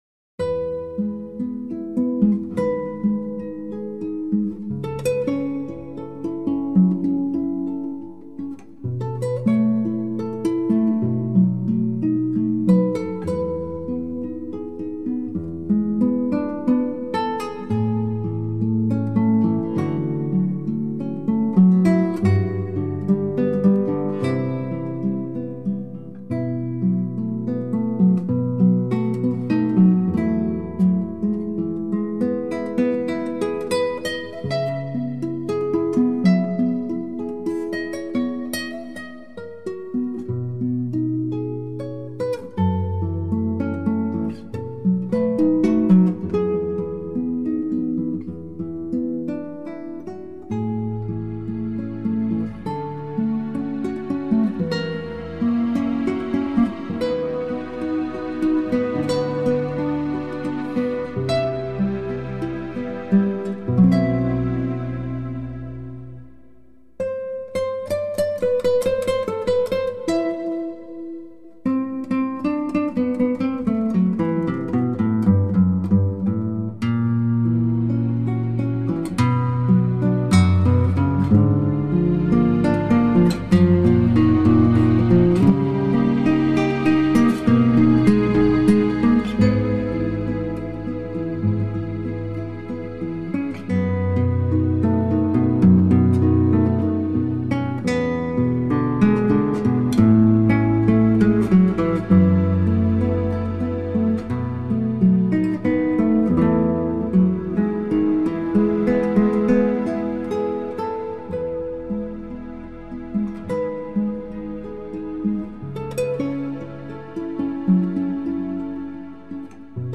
在充满繁复的高难度吉它技巧之中，洋溢著高水準的临场感录音，深深地打动您挑剔的耳朵，绝对包您直呼过癮，欲罢不能。